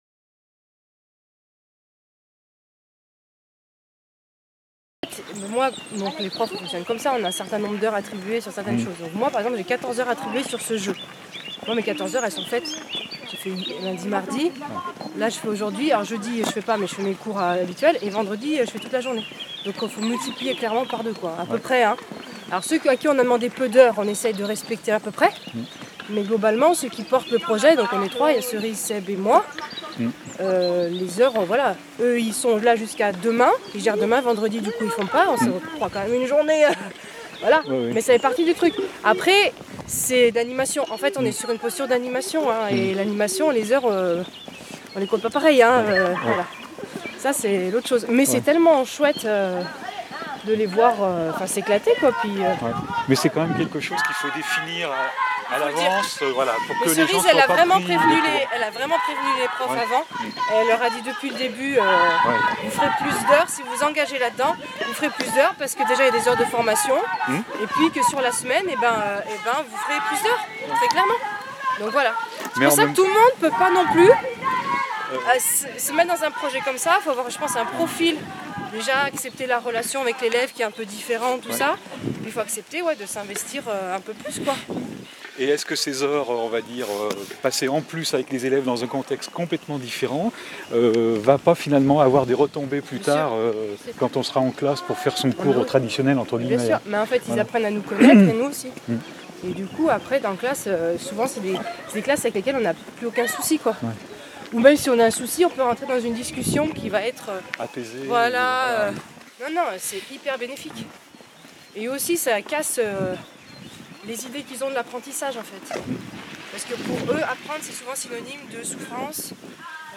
Interviews des enseignants des établissements participant au projet - Durée : 25'21")